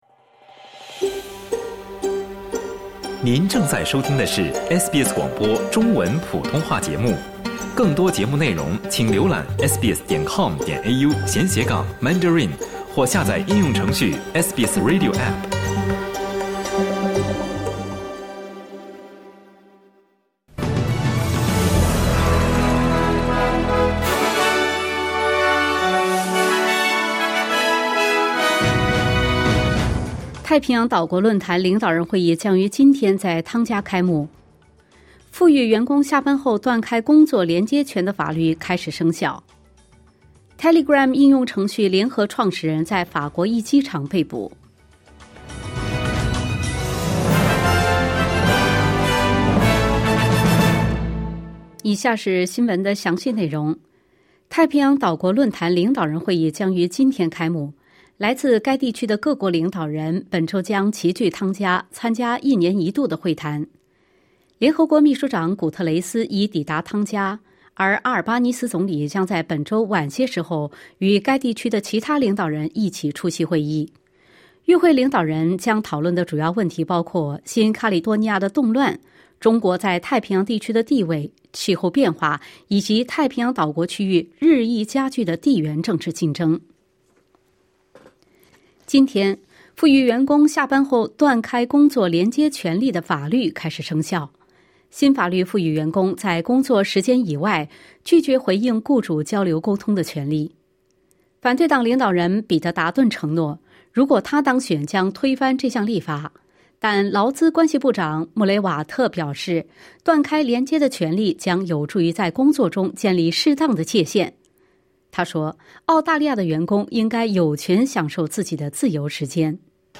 SBS早新闻（2024年8月26日）